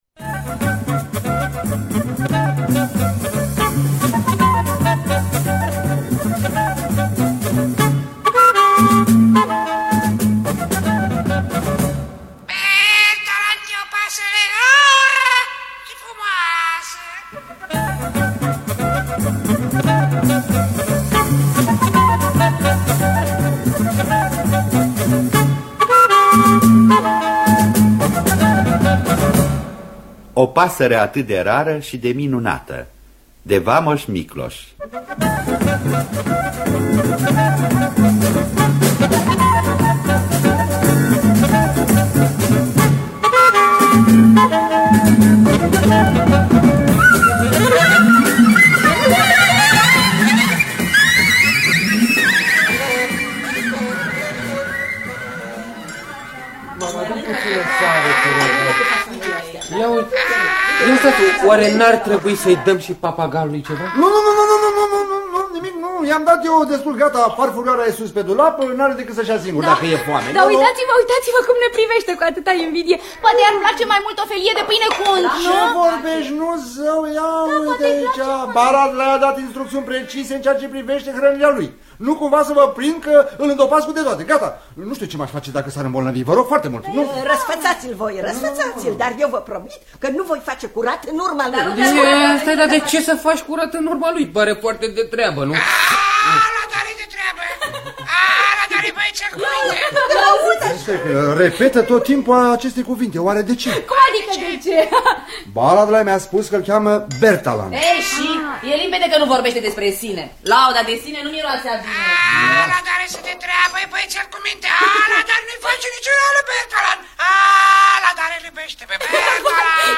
O pasăre atât de rară și de frumoasă de Vámos Tibor Miklós – Teatru Radiofonic Online